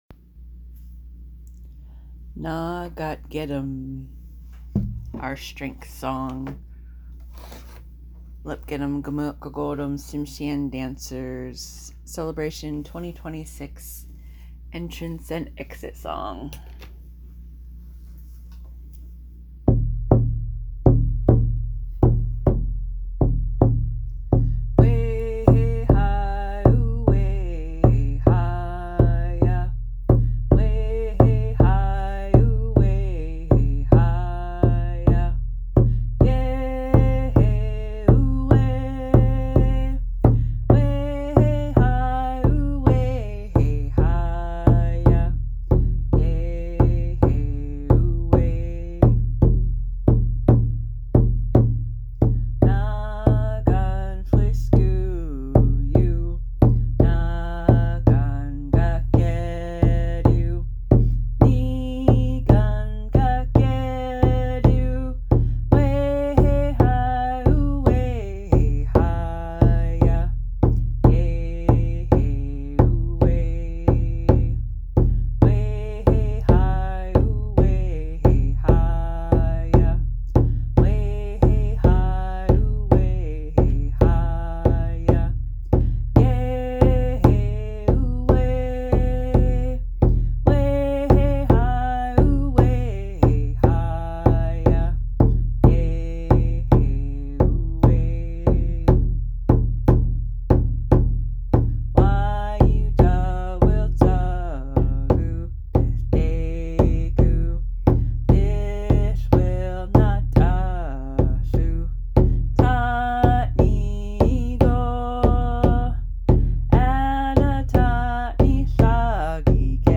Lepquinm Gumilgit Gagoadim Tsimshian Dancers, a multigenerational group from Anchorage, formed in 2005.
The lead dance group is responsible for leading the Grand Entrance and Grand Exit songs, during which every participating dance group dances across the stage to mark the beginning and end of Celebration. That requires the lead group to drum and sing for up to three hours straight during both processions.
LGG-Celebration-2026-entranceexit.mp3